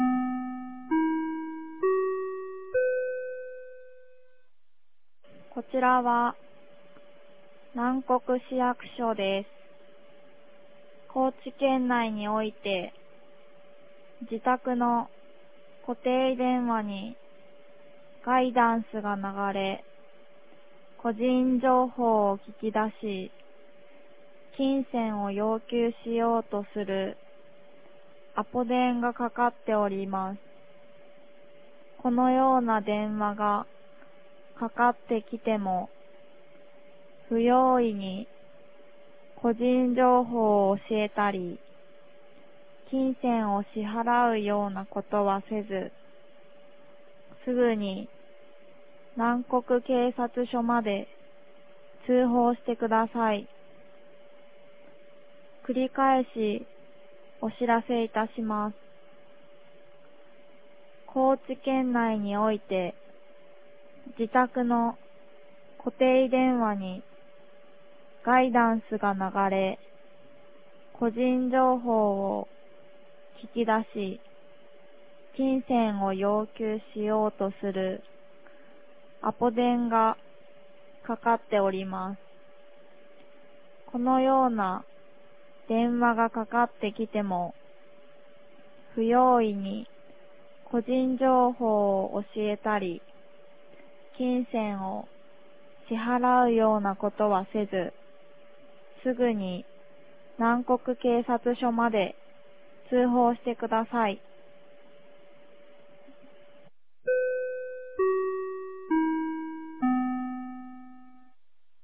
2023年07月11日 16時21分に、南国市より放送がありました。